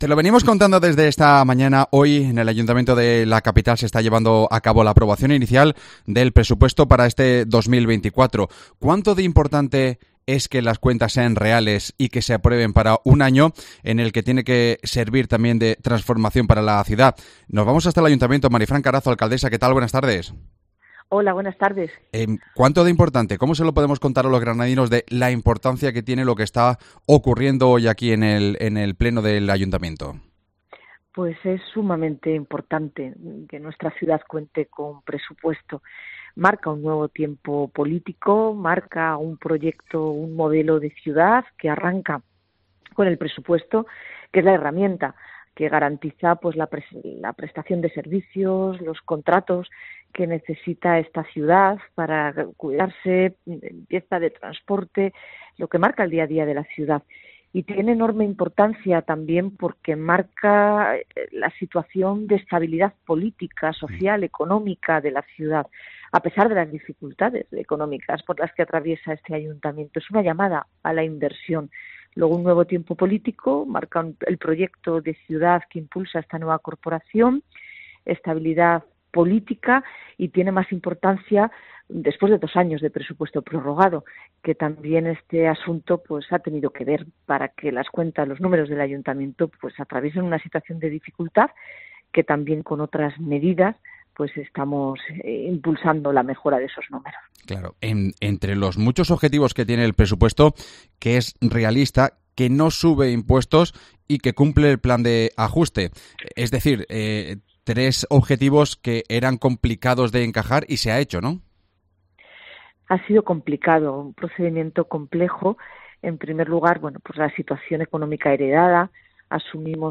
La alcaldesa de Granada ha explicado en COPE las cuentas que se han aprobado inicialmente este viernes